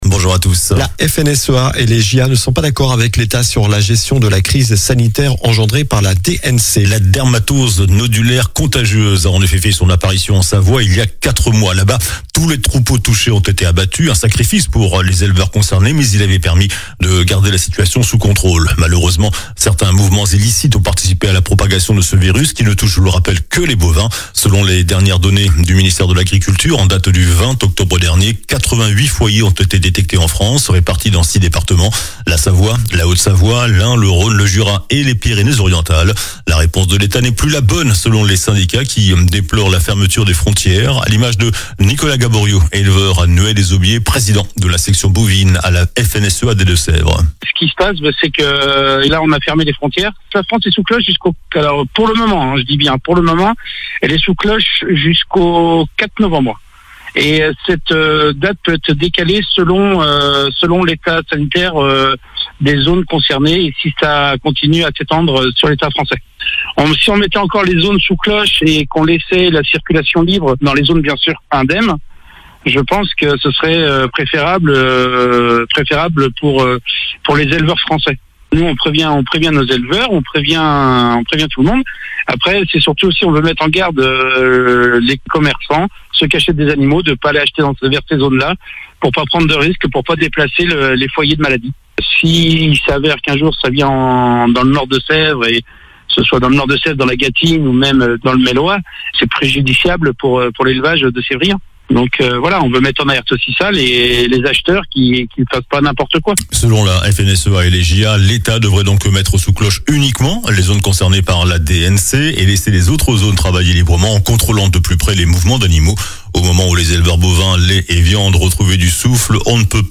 JOURNAL DU SAMEDI 25 OCTOBRE